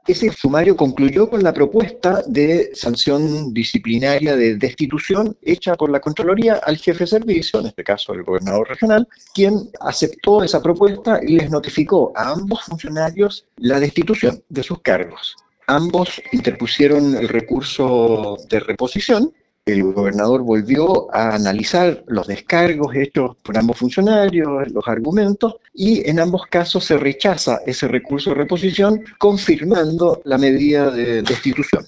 En conversación con La Radio